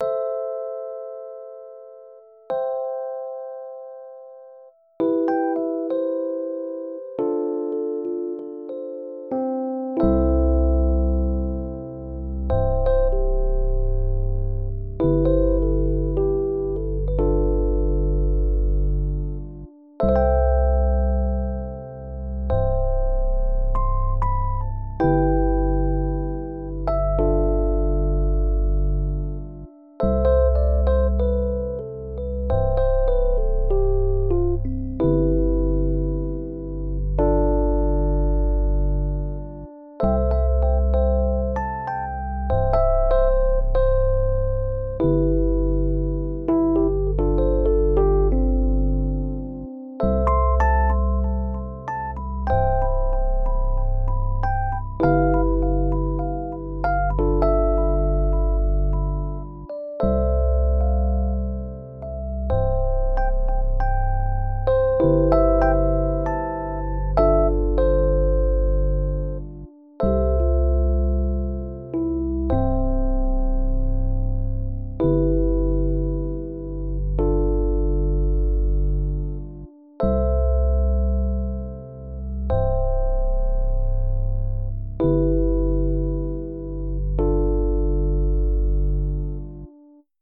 epiano.mp3